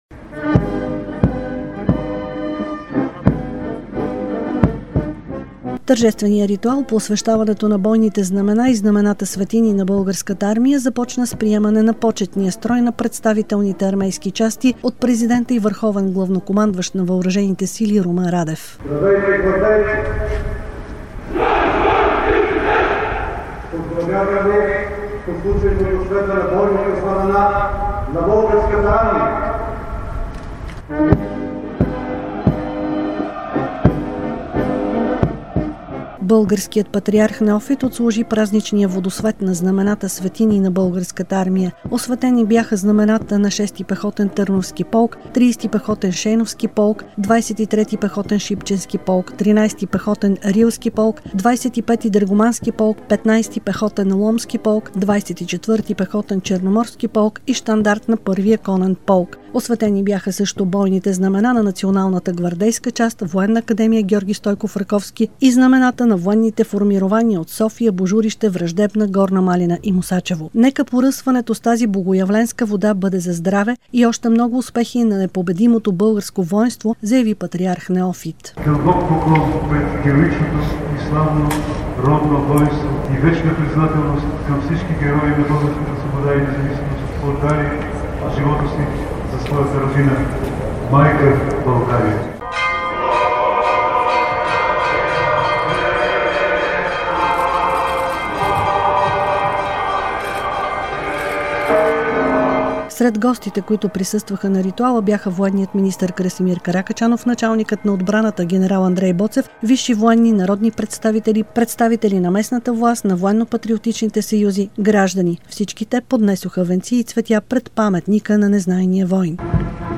Пред паметника на Незнайния воин в София патриарх Неофит отслужи традиционния Богоявленски водосвет на бойните знамена, флаговете и знамената-светини на Българската армия.
Повече за днешния празничен водосвет на бойните знамена и знамената -светини на Българската армия чуйте в репортажа